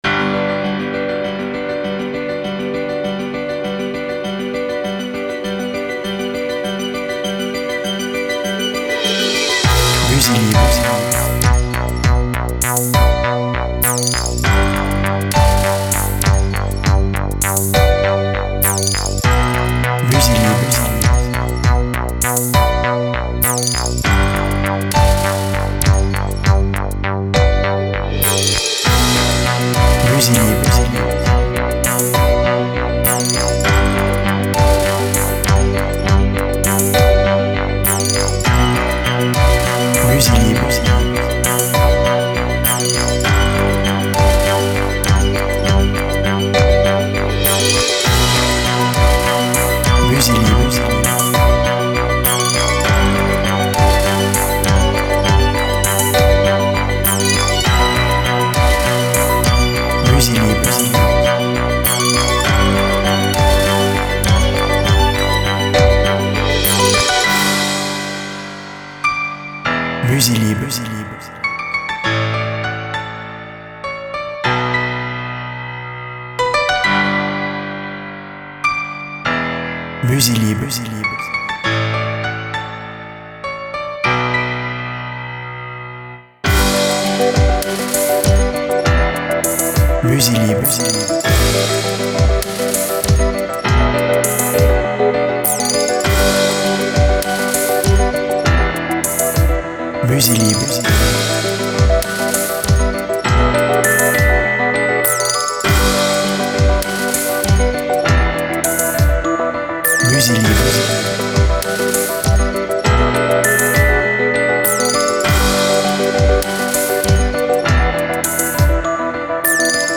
Musique des grands espace
BPM Lent